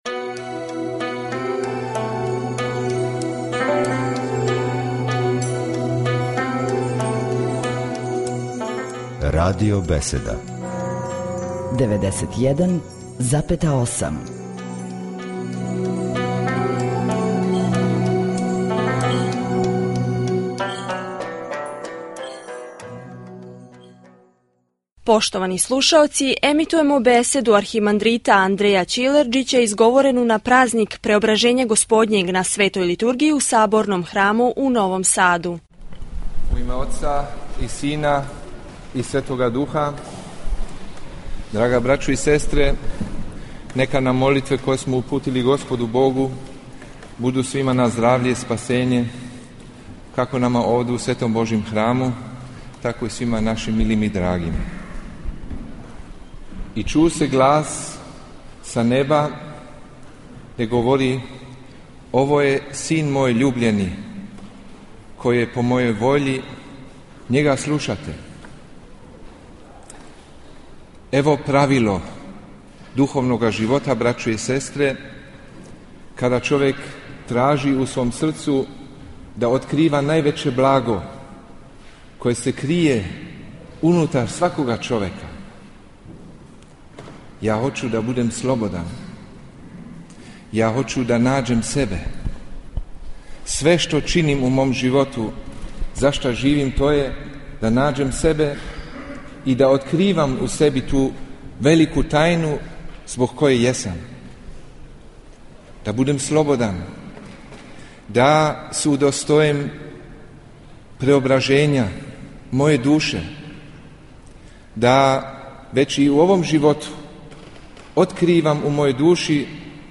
• Беседа Архимандрита Андреја Ћилерџића: